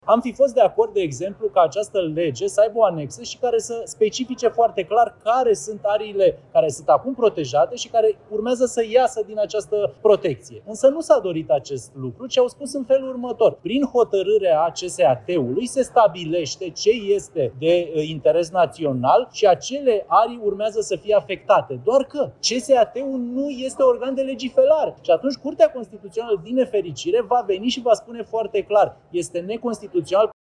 Deputatul USR Alexandru Dimitriu crede că legea va pica la Curtea Constituțională: „CCR va veni și va spune foarte clar: este neconstituțional”